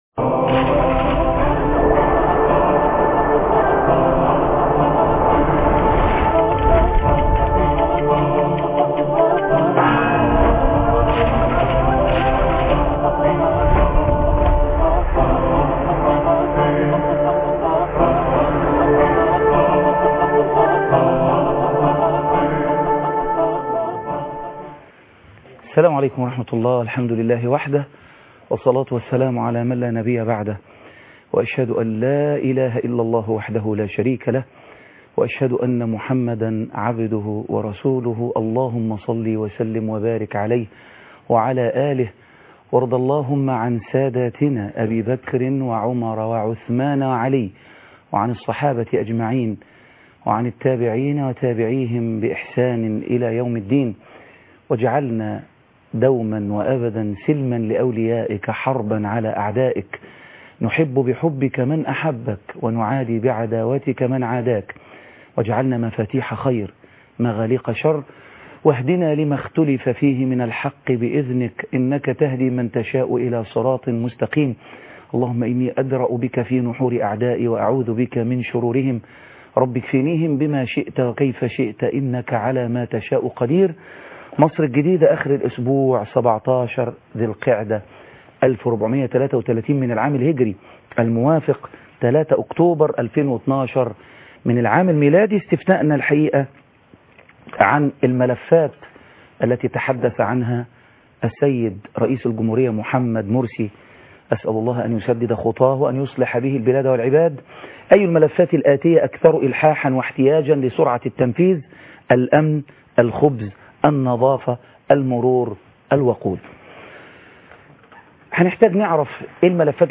لقاء مع نادر بكار عن الاختلافات فى حزب النور ، مشاكل شركات سياحة الحج (3/10/2012) مصر الجديدة - الشيخ خالد عبد الله